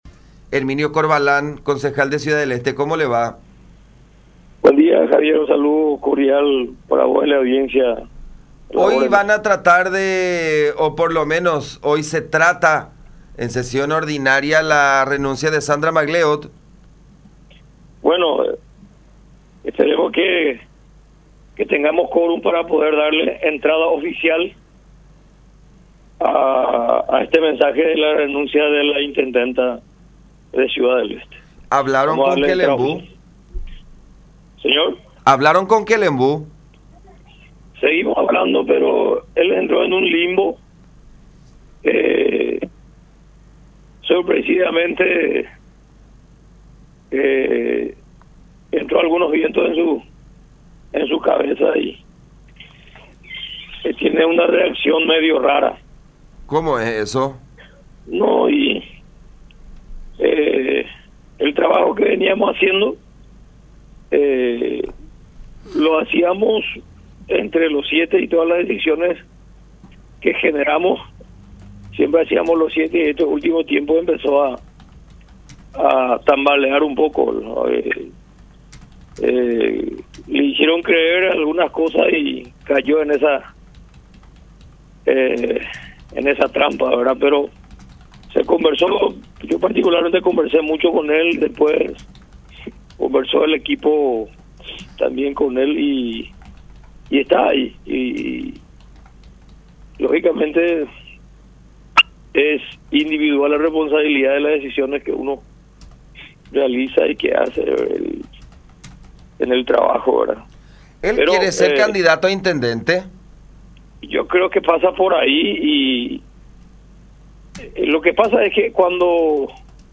“Lastimosamente cayó en esta trampa”, expuso Corvalán en comunicación con La Unión, subrayando que esta idea “le metió en la cabeza” el actual gobernador de Alto Paraná, Roberto González Vaesken.
03-CONCEJAL-HERMINIO-CORVALÁN.mp3